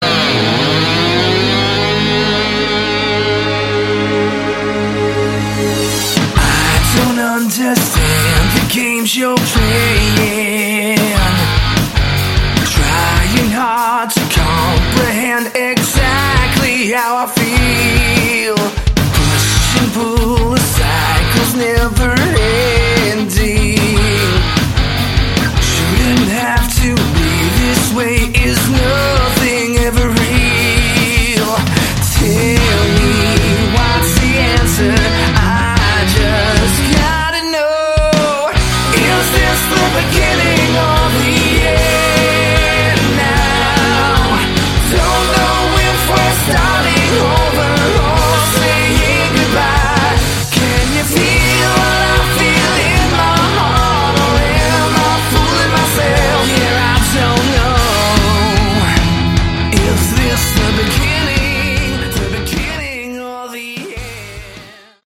Category: Melodic Rock
guitar, vocals
drums, vocals
bass, vocals